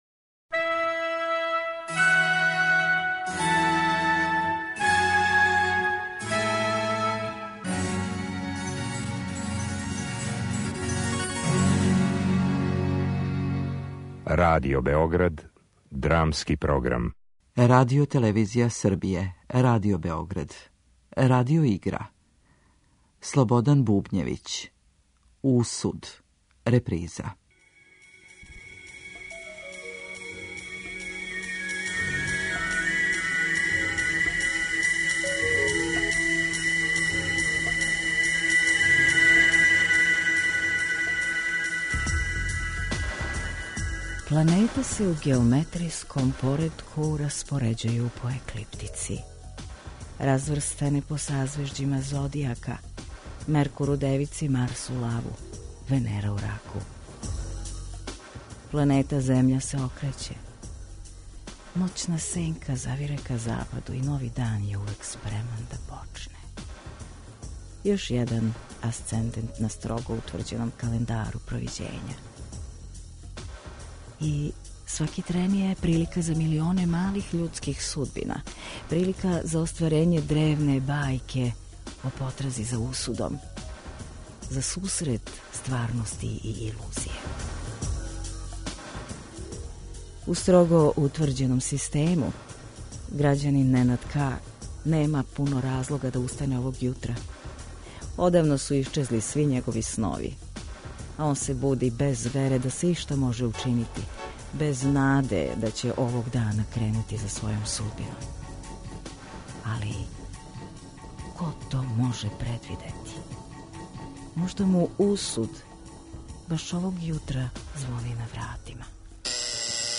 Драмски програм: Радио игра
Радио игра